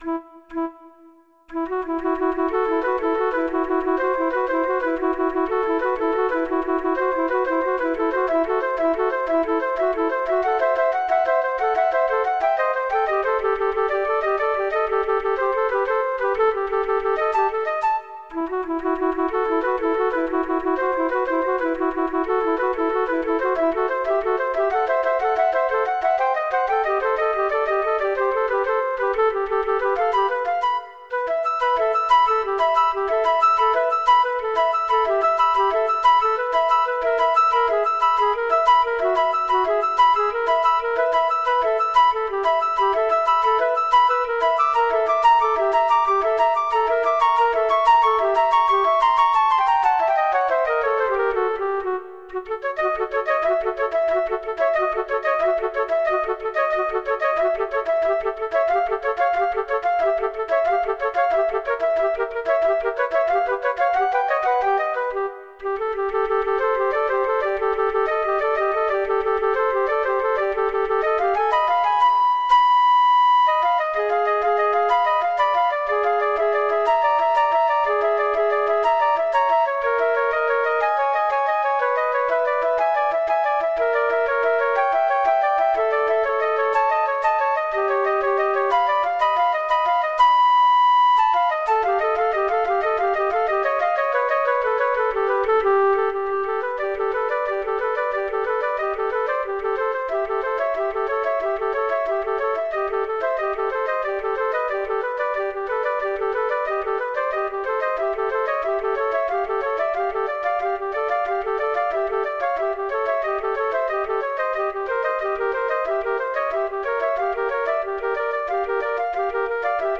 This trio for three flutes is full of aural illusions.
Then without metronome clicks,
with the first flute missing there are